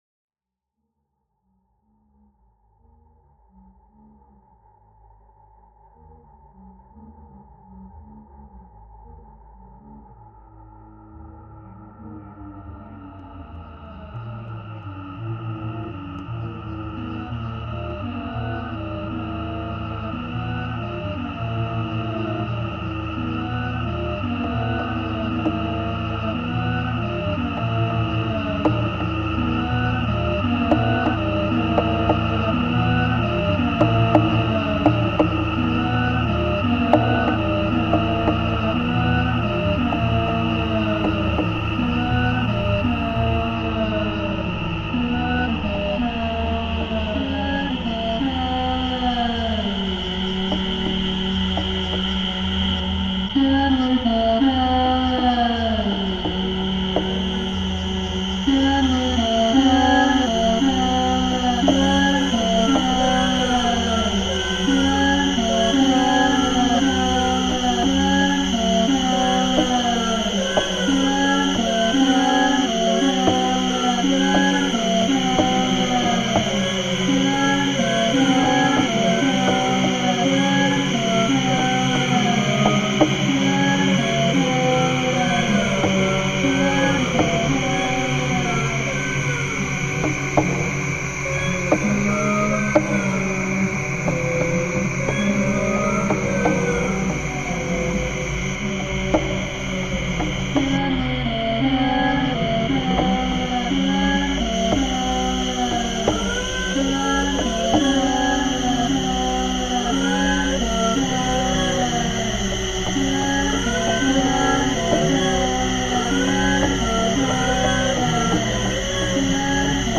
Shipibo icaro